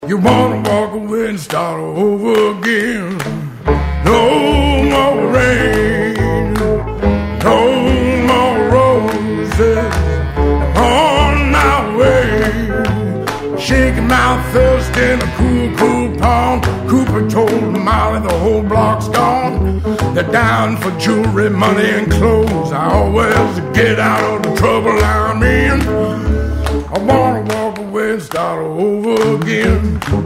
• Качество: 128, Stereo
джаз